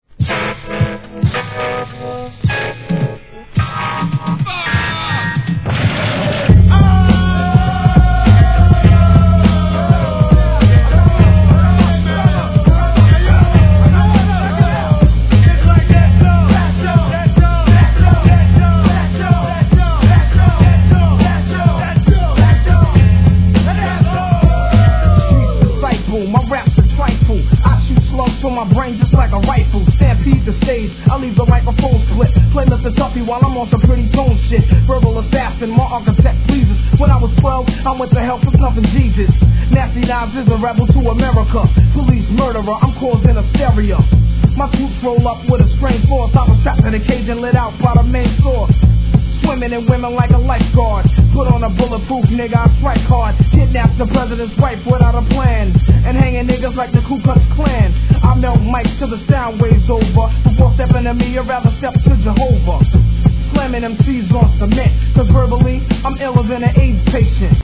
HIP HOP/R&B
音圧もばっちり!